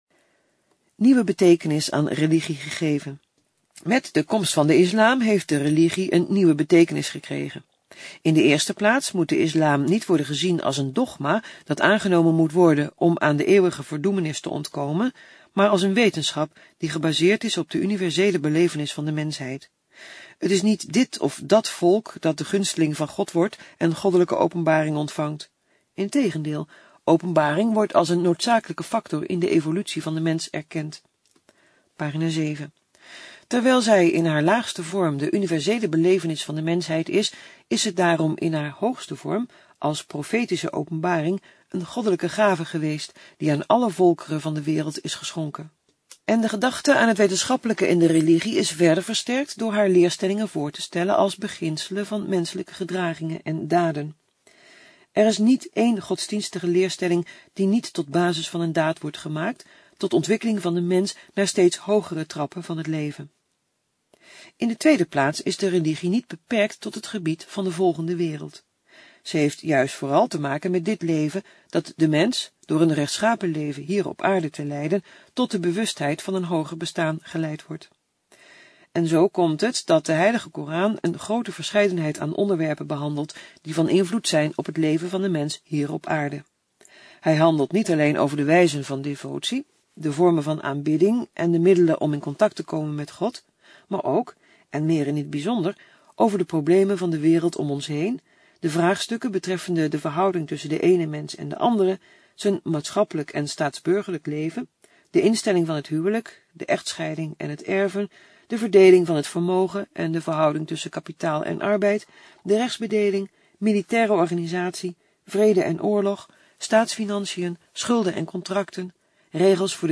Fragment van de gesproken tekst.